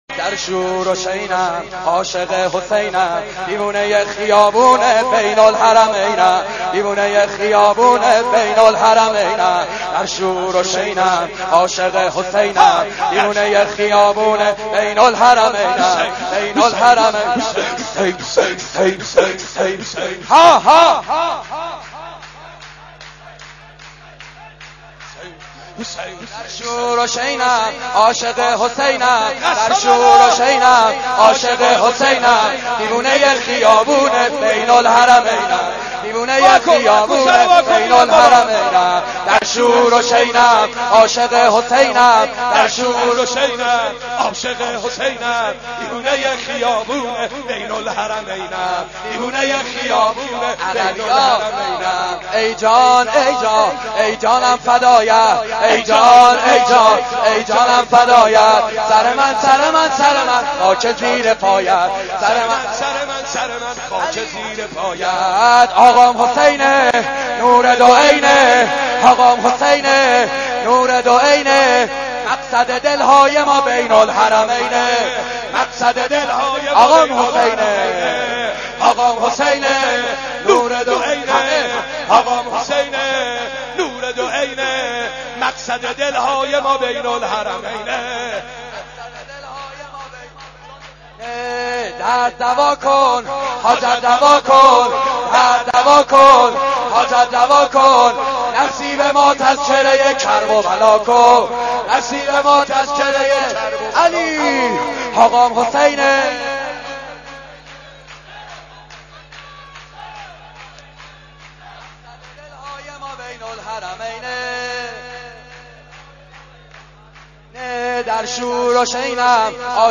سايت مظلومانه : مولودی و مراسم جشن ائمه اطهار